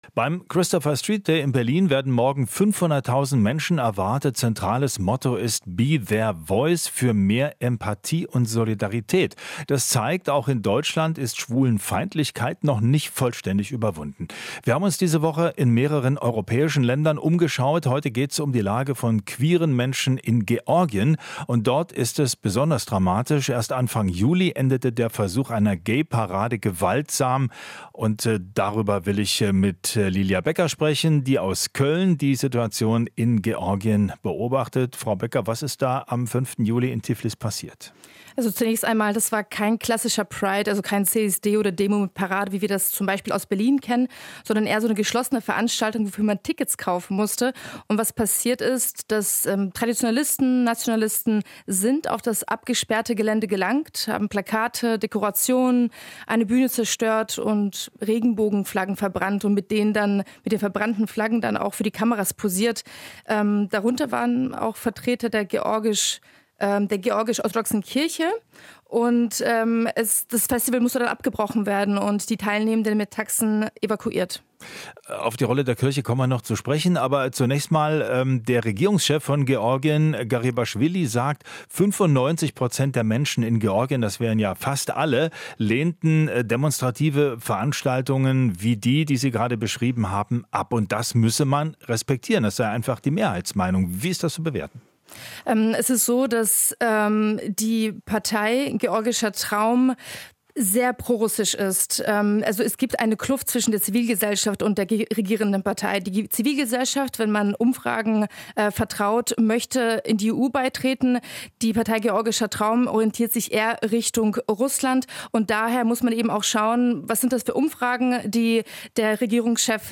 Interview - Homophobie bedroht die LGBTQ-Community in Georgien